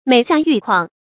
每下愈況 注音： ㄇㄟˇ ㄒㄧㄚˋ ㄧㄩˋ ㄎㄨㄤˋ 讀音讀法： 意思解釋： 越往下越明顯。表示情況越來越壞。